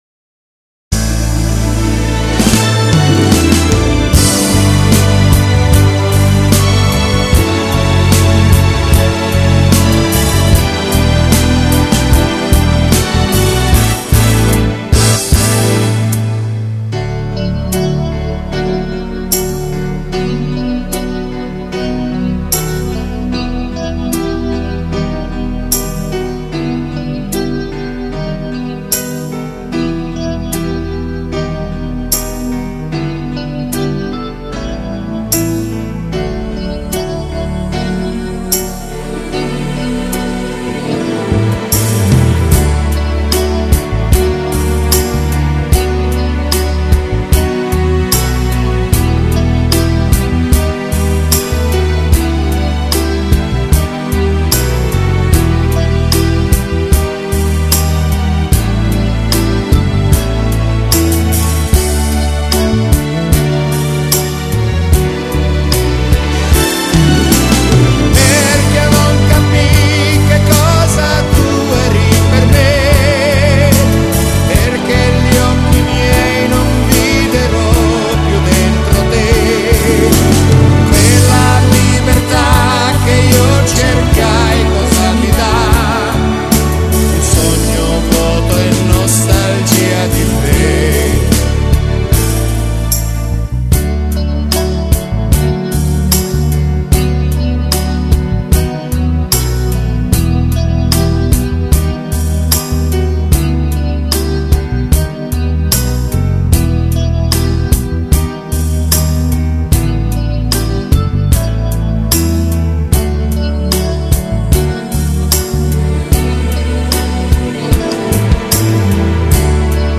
Genere: Lento